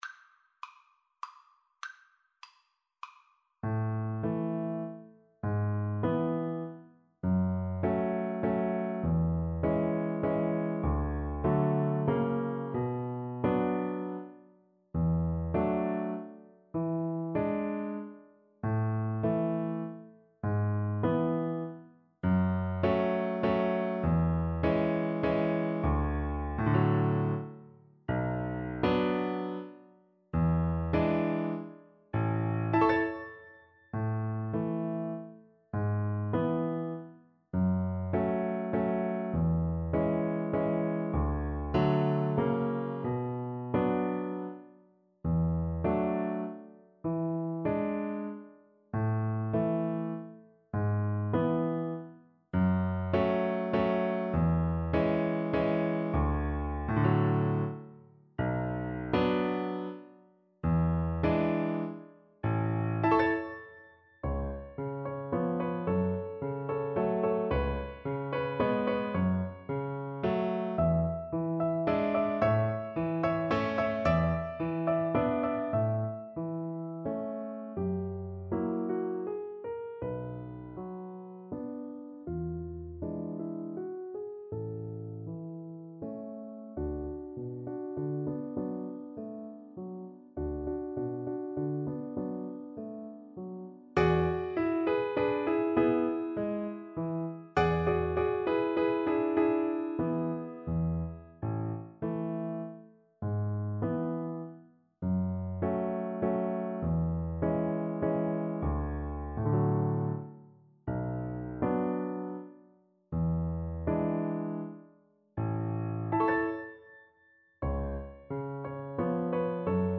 3/4 (View more 3/4 Music)
A major (Sounding Pitch) (View more A major Music for Violin )
~ = 100 Tranquillamente